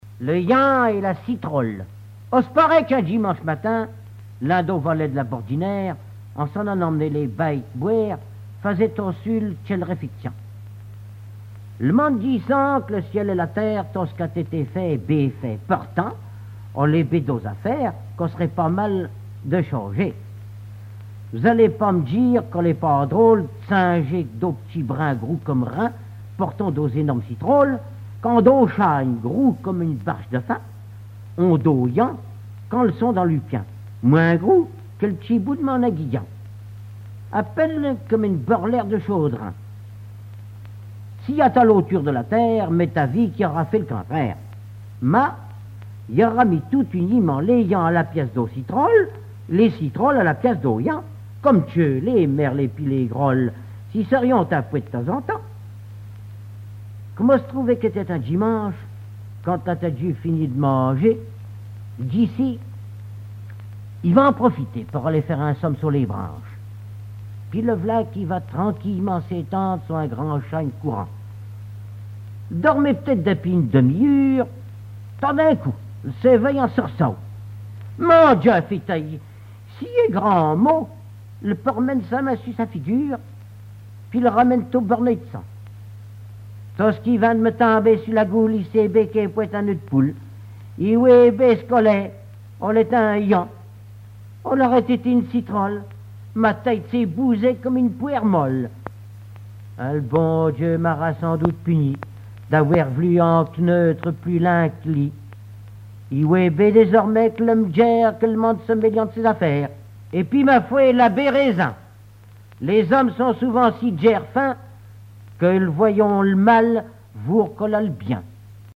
Genre fable
Alouette FM numérisation d'émissions